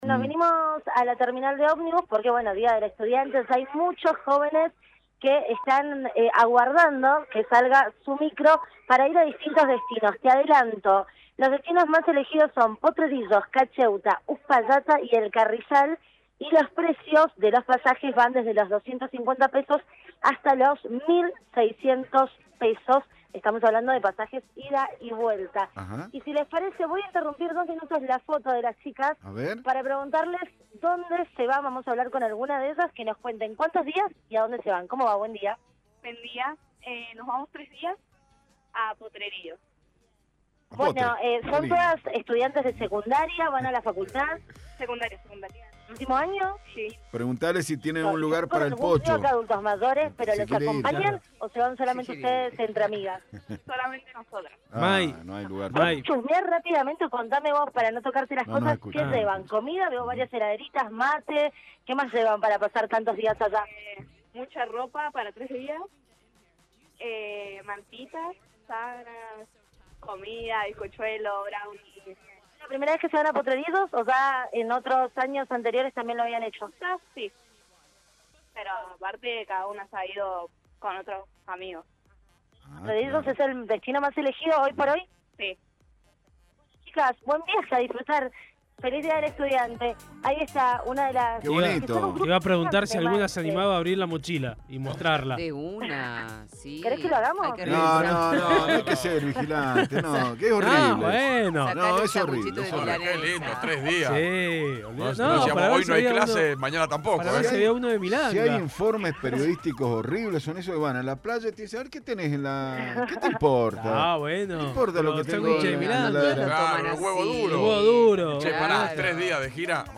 LVDiez - Radio de Cuyo - Móvil de LVDiez desde Terminal de Omnibus- Día del estudiante y la primavera.